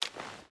Water Run 2.ogg